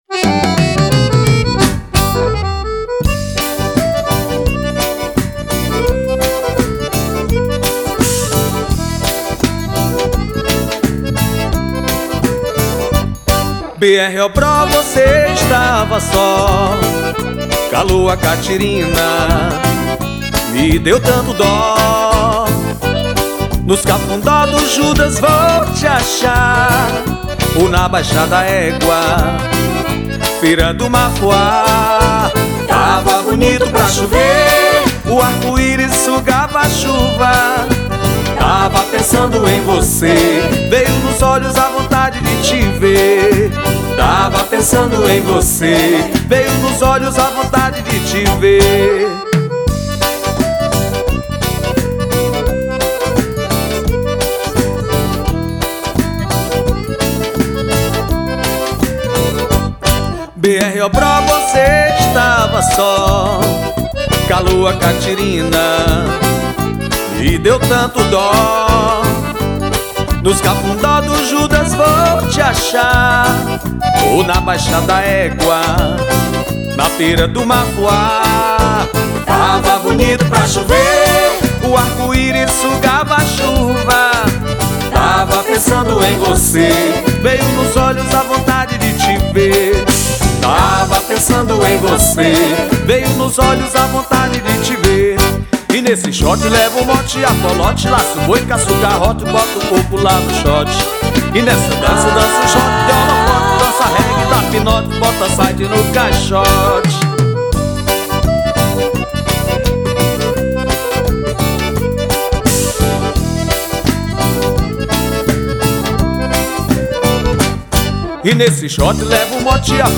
2534   02:37:00   Faixa: 13    Baião